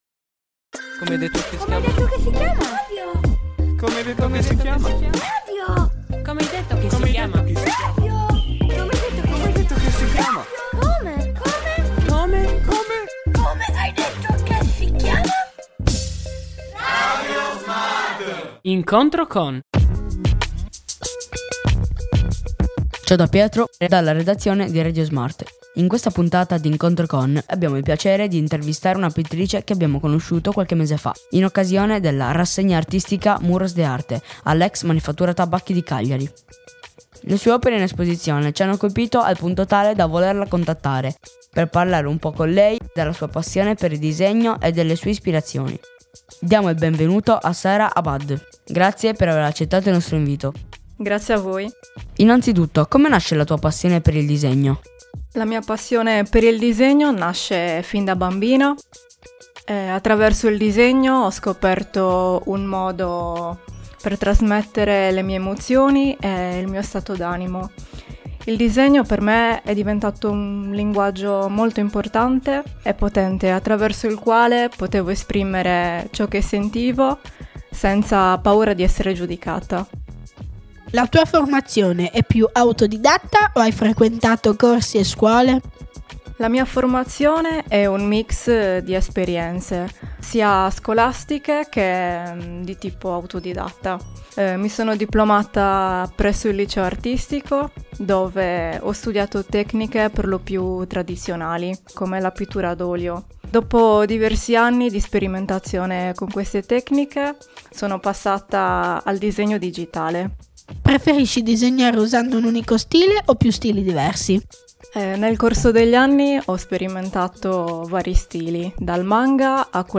In questa intervista ci racconta le sue ispirazioni e i suoi progetti artistici.